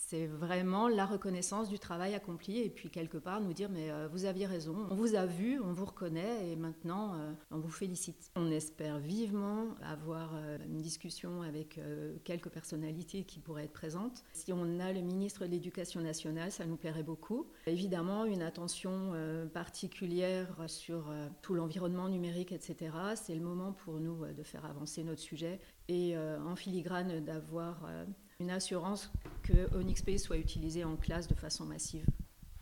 Des interviews sur les ondes de Chérie FM et ICI Belfort-Montbéliard ont permis de donner la parole à sa créatrice